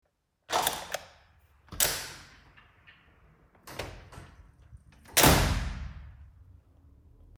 Сборник звуков открывания и закрывания деревянных и металлических дверей
dver-mnogokvarturnogo-doma.mp3